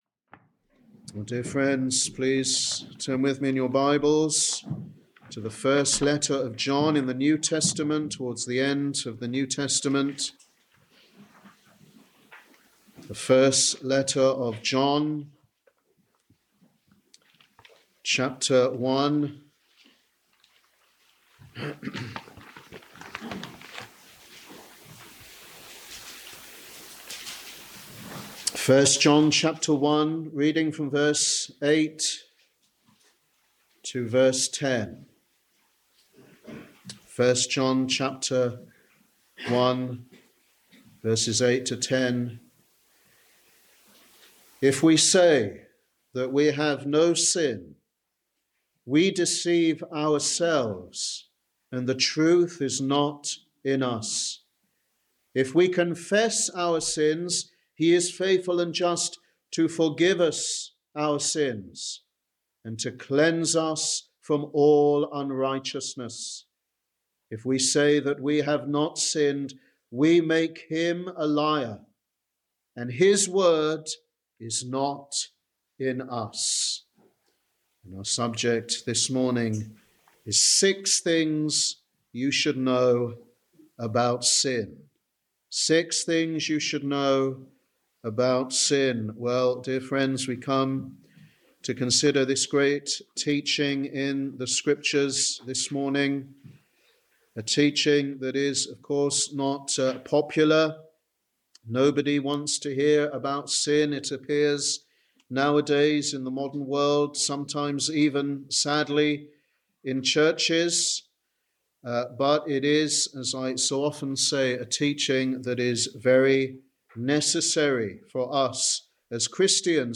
Sunday Evangelistic Service
Sermon